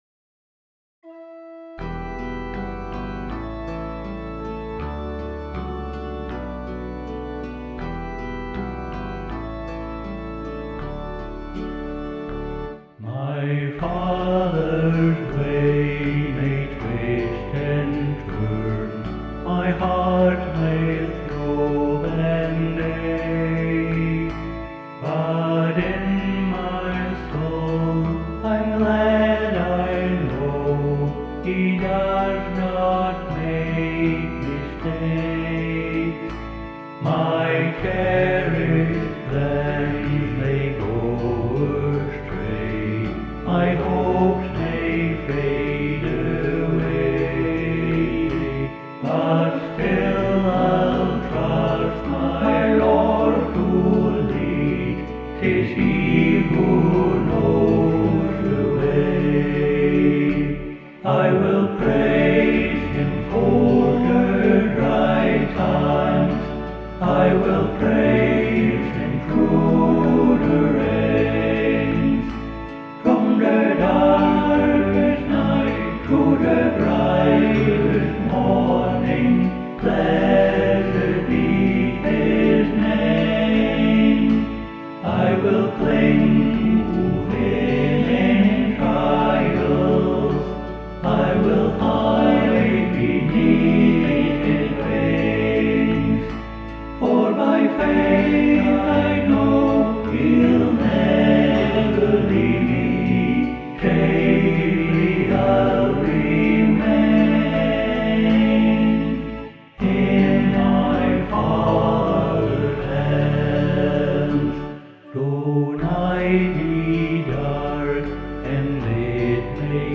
SATB